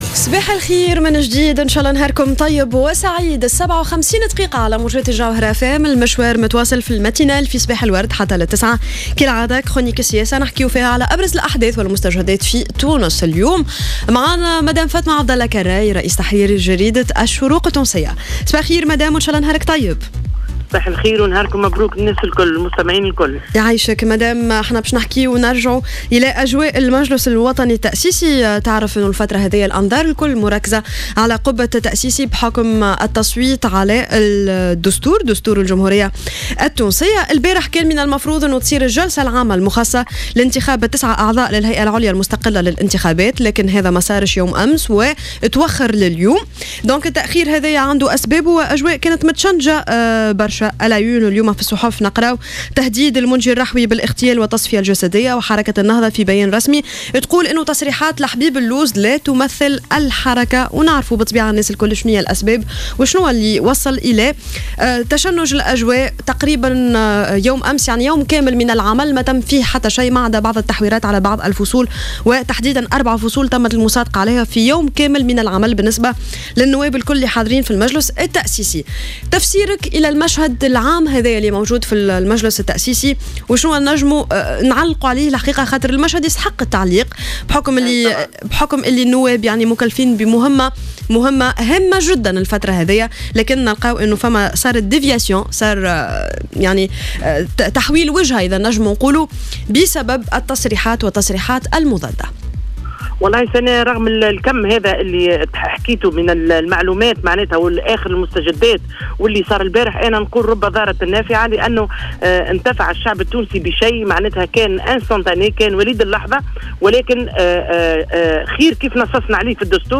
مداخلة لها اليوم على "جوهرة أف أم" أن الديمقراطية لا تستوي دون معارضة ودون أقلية،مشيرة إلى أن الديمقراطية لا تعني حكم الأغلبية وإنما رأي الأقلية.